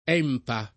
vai all'elenco alfabetico delle voci ingrandisci il carattere 100% rimpicciolisci il carattere stampa invia tramite posta elettronica codividi su Facebook ENPA [ $ mpa ] n. pr. m. — sigla di Ente Nazionale per la Protezione degli Animali